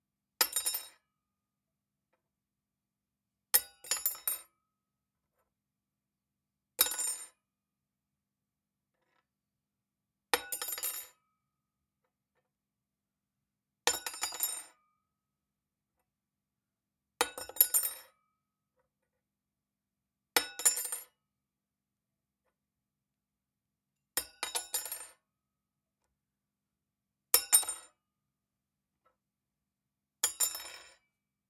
Cutlery Fork Knife Spoon Metal Dropped On Floor Pack
Armour Blacksmith Blade Chopping Cut Cutlery Ding Draw sound effect free sound royalty free Sound Effects